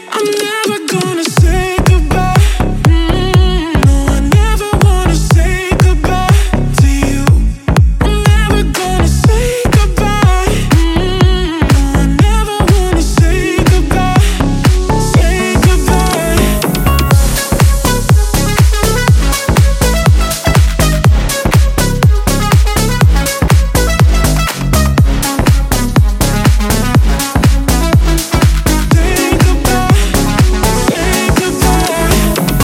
Жанр: Танцевальная